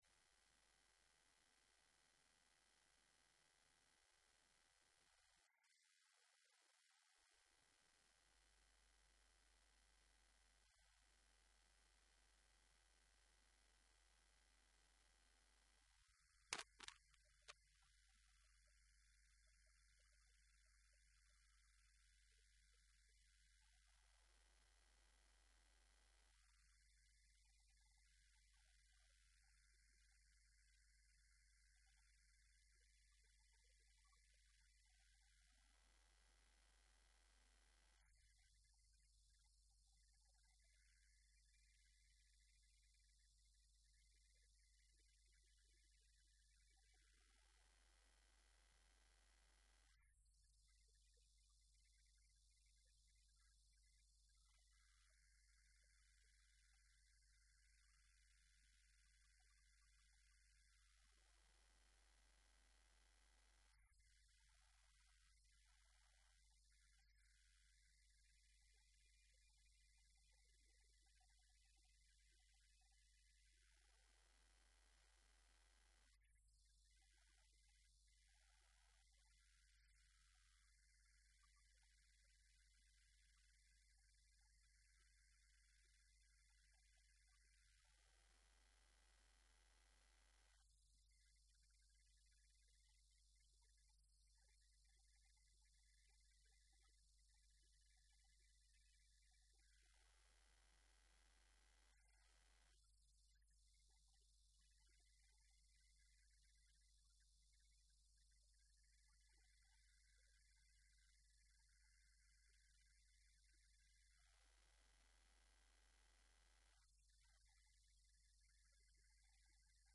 الخطبه
خطب الجمعة